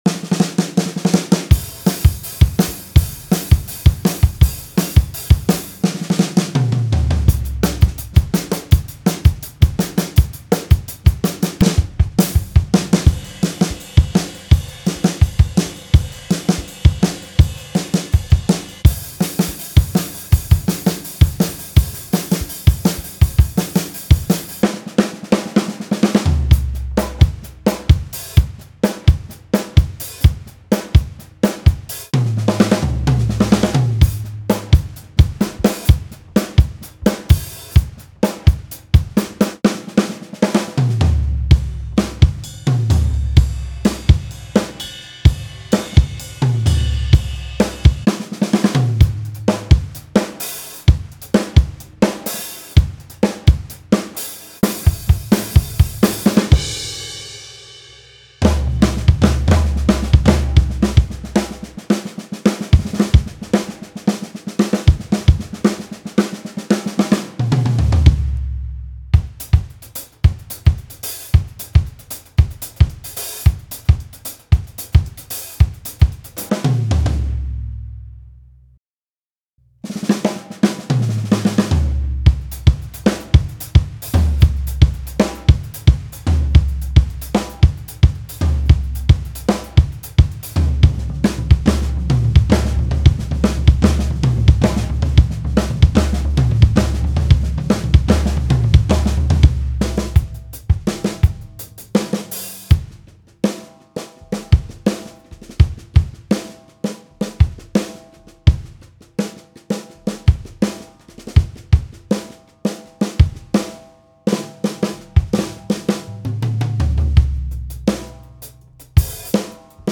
20 Acoustic Drum Beats: A diverse selection of drum beats recorded with exceptional clarity and a natural feel. From smooth and laid-back grooves to upbeat and driving rhythms, these beats are perfect for setting the tone of your indie tracks.
20 Acoustic Drum Fills: Creative and energetic drum fills that add excitement and seamless transitions to your music.
7 One-Shots: High-quality individual drum hits, including kicks, snares, hi-hats, and more.
High-Quality Audio: Professionally recorded and mixed to capture the full, rich sound of acoustic drums.